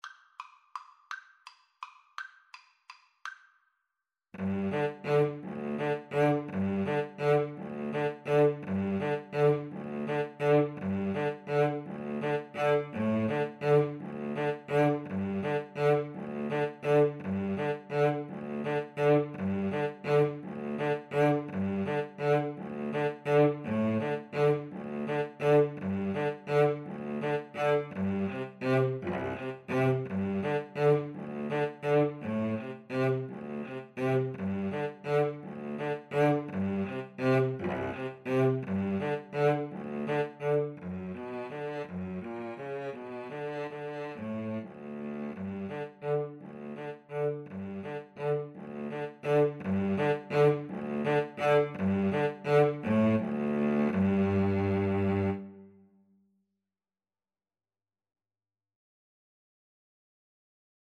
Tempo di valse =168
Classical (View more Classical Cello Duet Music)